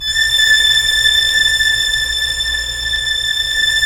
Index of /90_sSampleCDs/Roland LCDP13 String Sections/STR_Symphonic/STR_Symph.+attak